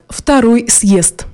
Звуки навигатора